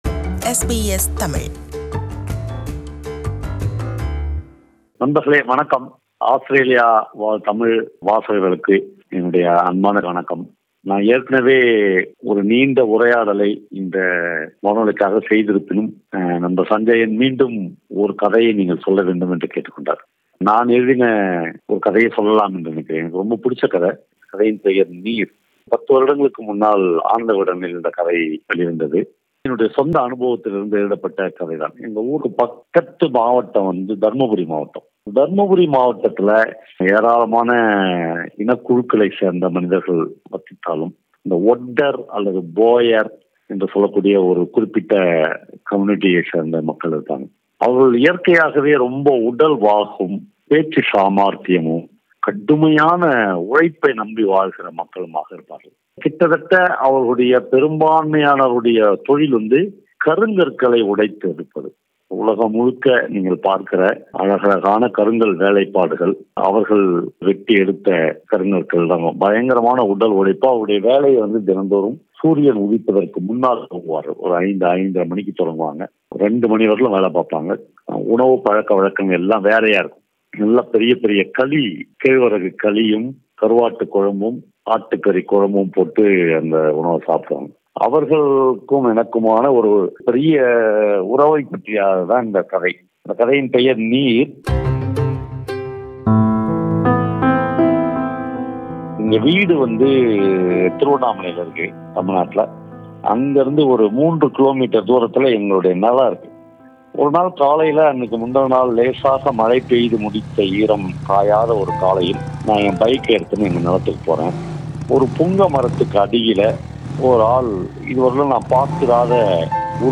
He has a unique way of narrating stories.